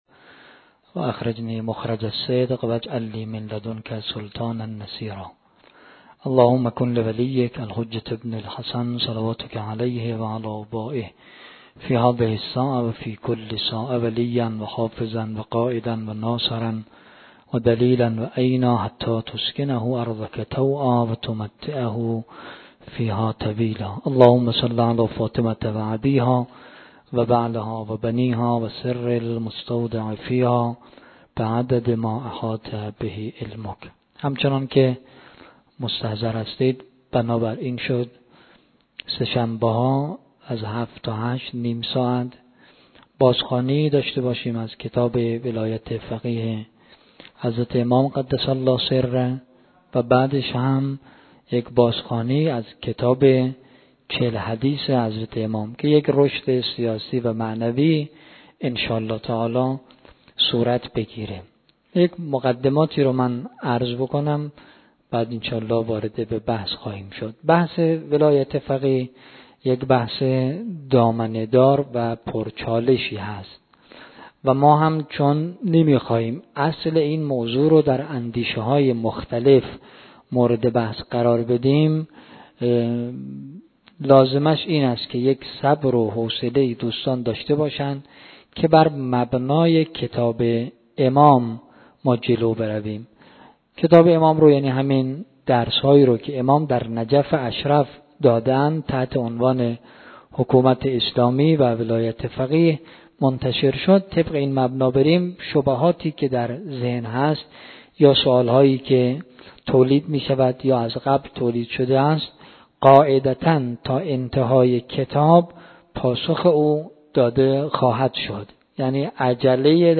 در نخستین جلسه از سلسله جلسات «رشد معنوی سیاسی» که با هدف بازخوانی کتب ولایت فقیه و چهل حدیث امام خمینی(ره) در مسجد 72 تن مجموعه سرچشمه برگزار شد